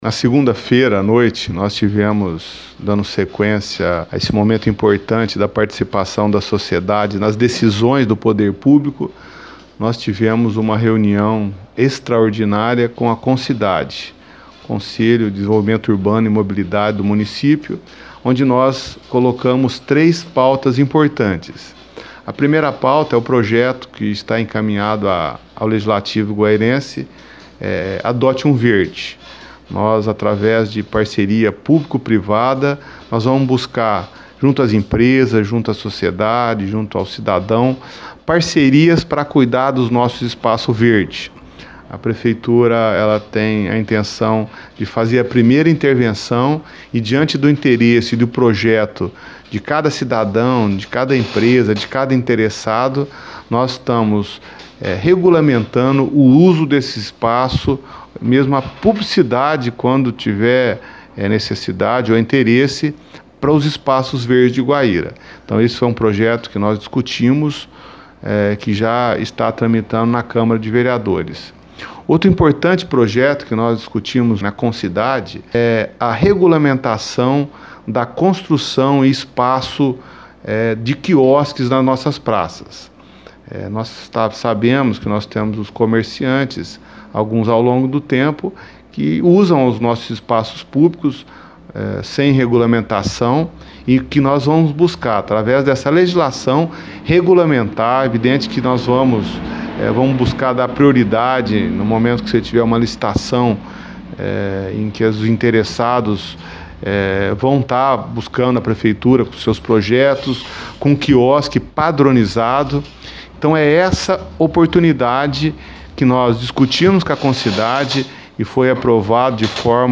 OUÇA: Prefeito José Eduardo Coscrato Lelis:
José-Eduardo-Coscrato-Lelis-Reunião-Concidade.mp3